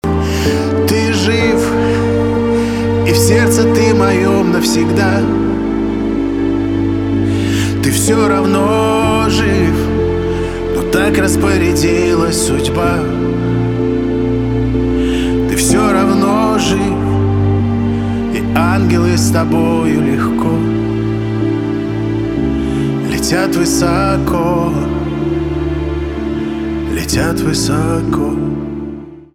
поп